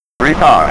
retard.wav